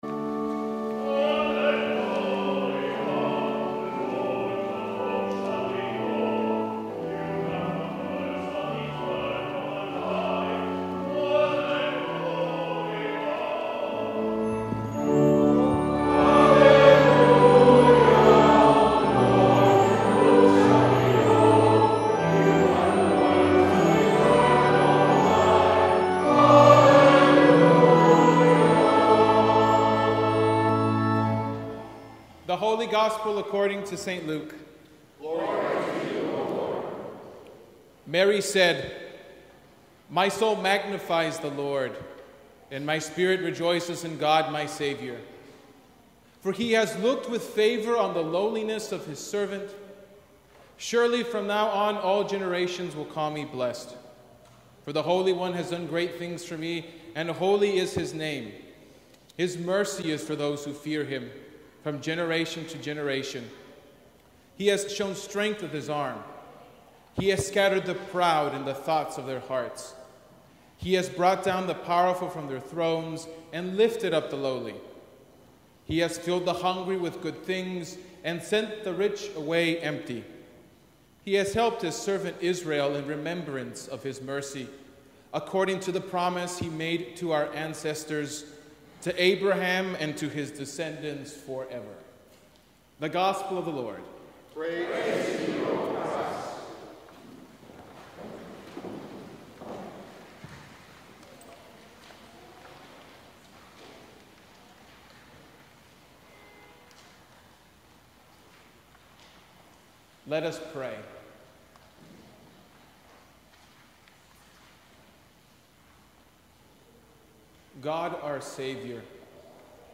Sermon from the Thirteenth Sunday After Pentecost